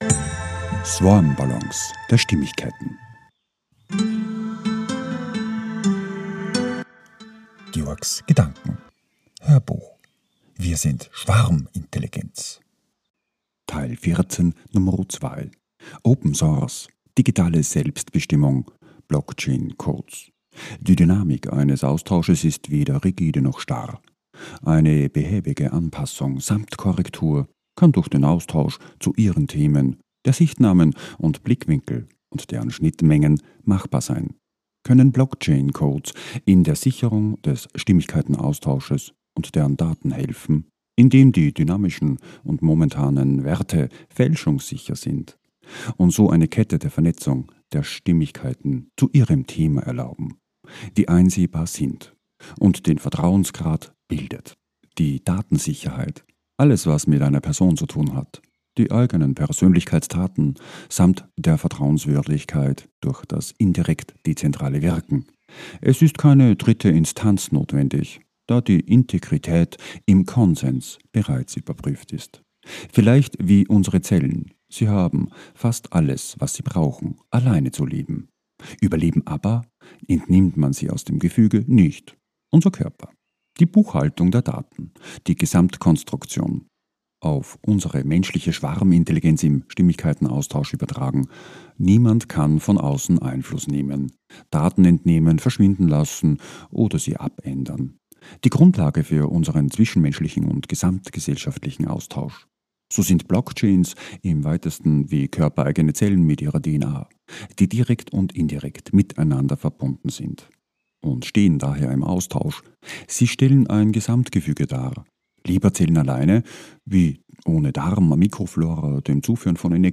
HÖRBUCH - 014:2 - WIR SIND SCHWARMINTELLIGENZ - Open Source, digitale Autonomie & BLOCKCHAINS